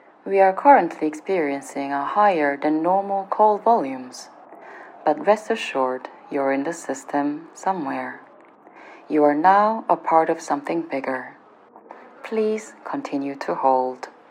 Operator_filtered6.mp3